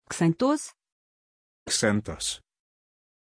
Pronunția numelui Xanthos
pronunciation-xanthos-ru.mp3